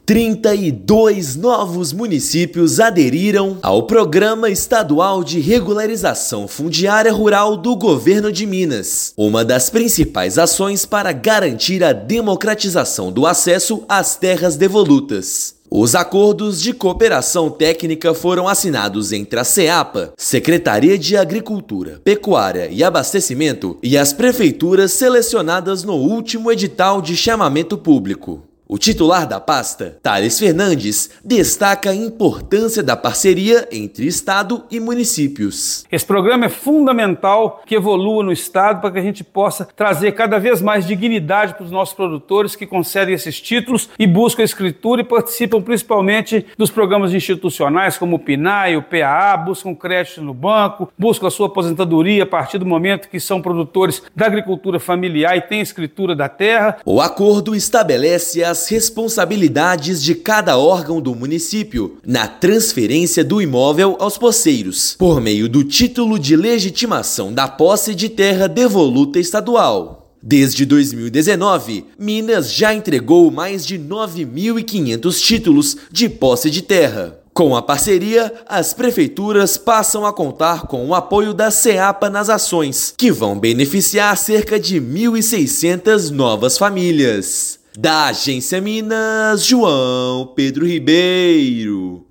A assinatura dos Acordos de Cooperação Técnica deve beneficiar cerca de 1,6 mil famílias em 32 municípios, que passam a ter acesso a crédito e políticas públicas. Ouça matéria de rádio.